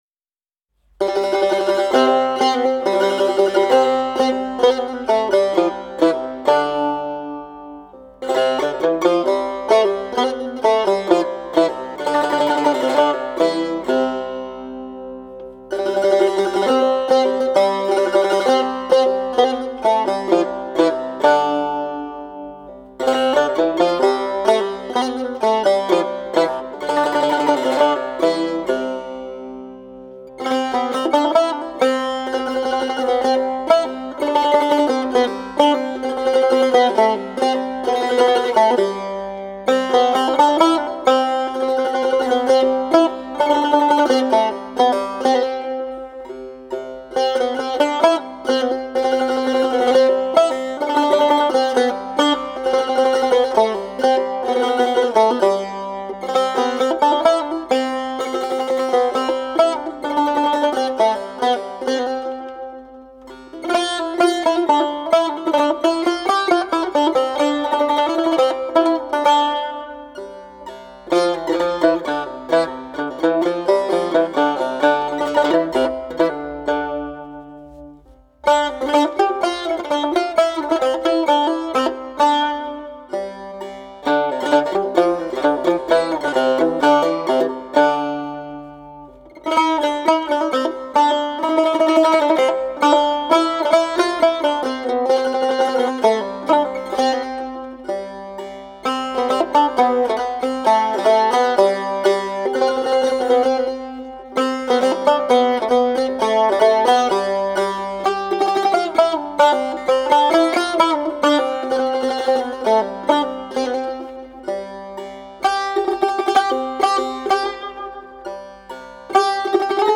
آموزش پیش درآمد ماهور برای سه تار